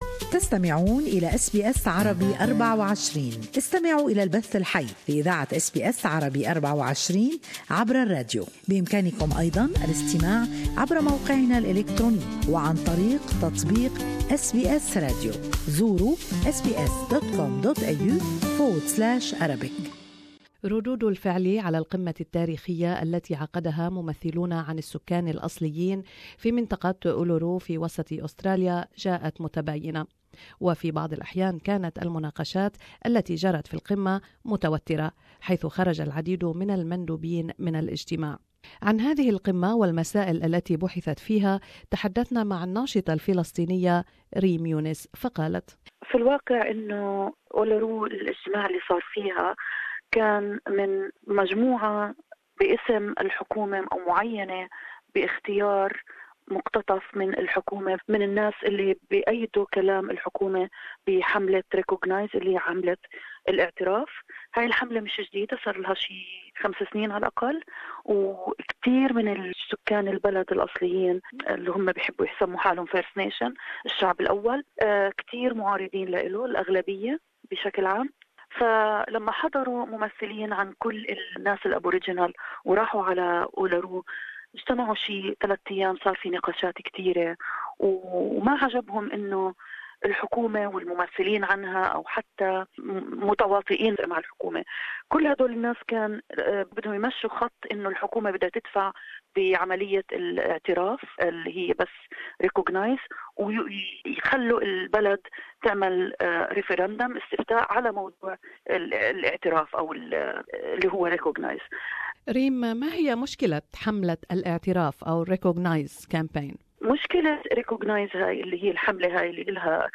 Reaction and analysis of the Uluru Summit by Palestinian activist